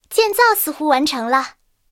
I号建造完成提醒语音.OGG